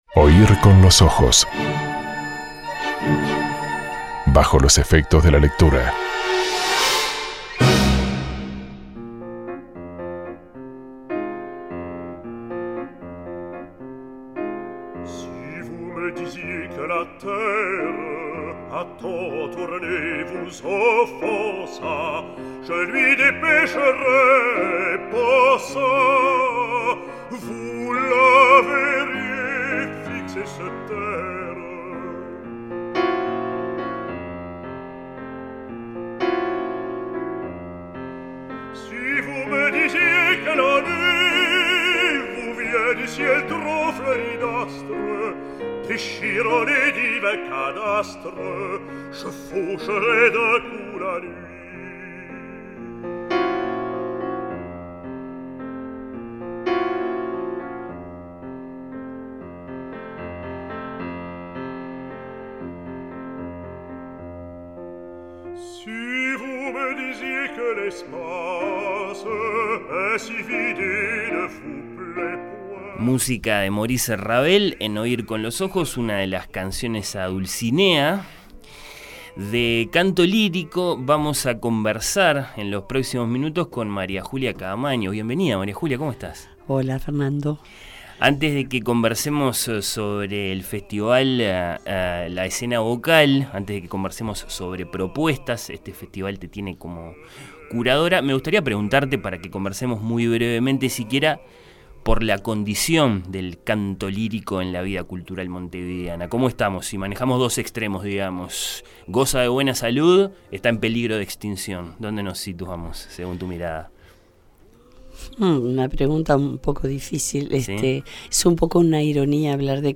una conversación